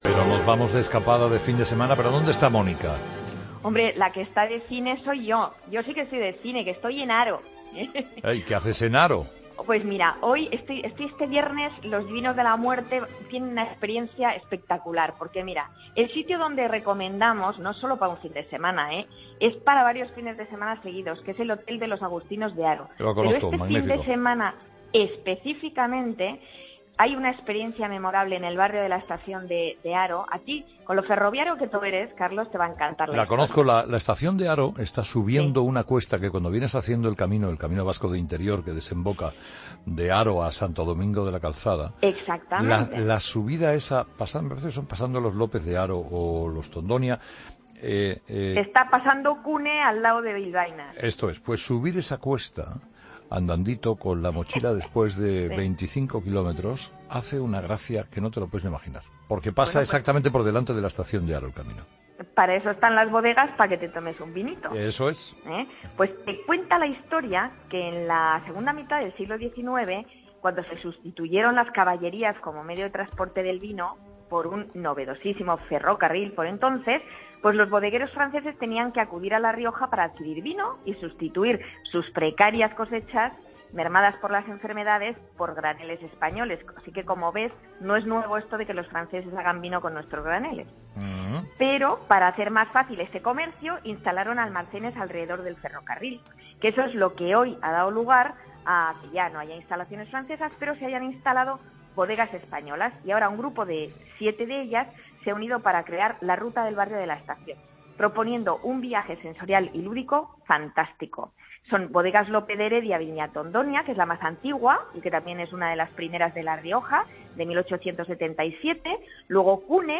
desde Haro, nos habla de los lugares con más encanto de esta localidad riojana en plena vendimia